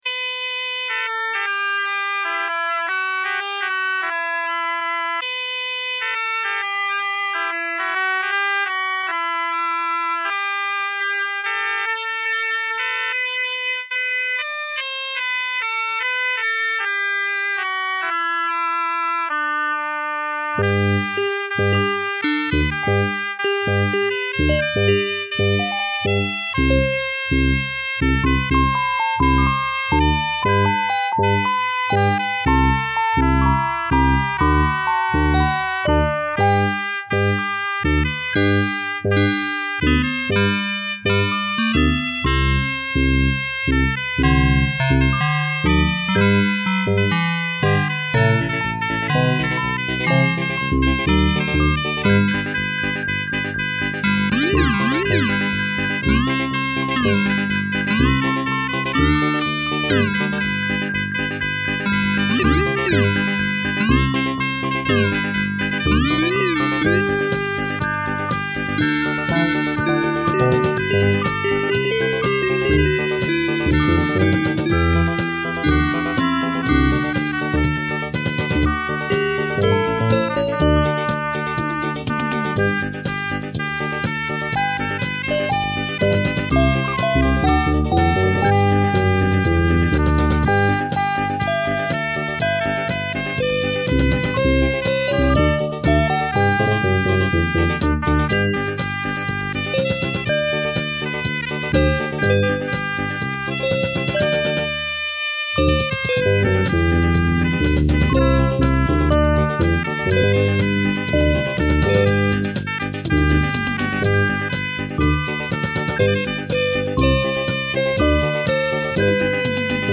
This is a medley.
My only excuse is that I was very young at the time, and perhaps a little too ambitious for my trusty Yamaha PortaSound.
xmas_medley.mp3